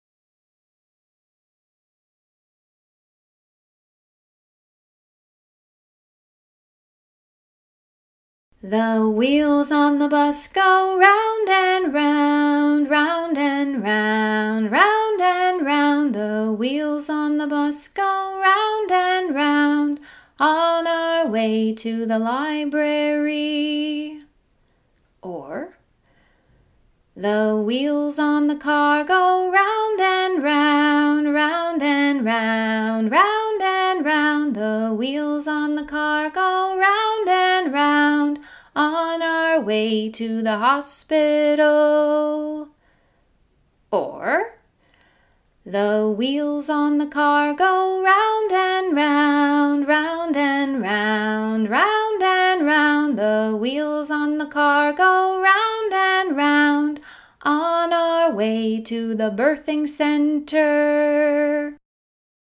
Songs & Rhymes